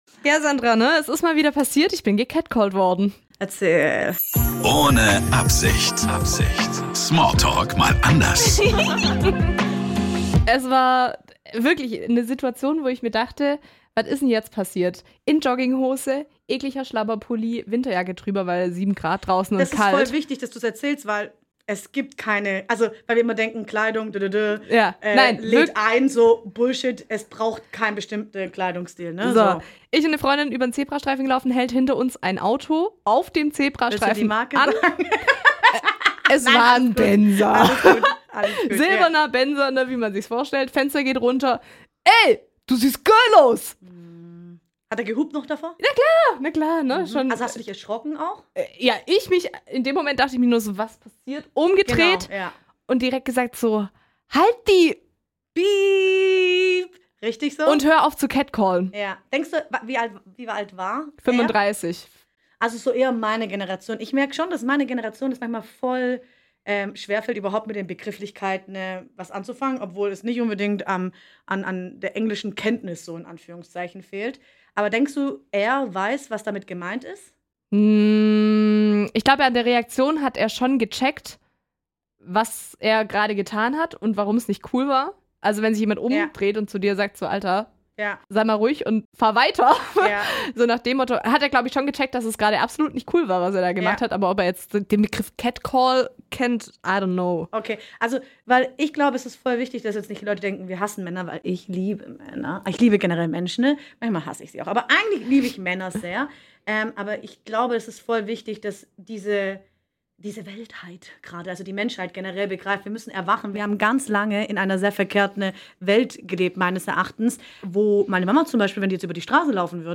Ein ehrliches Gespräch über Momente, die einfach nicht sein müssen, persönliche Erlebnisse und der Wunsch, dass eine solche Unterhaltung bald überflüssig wird.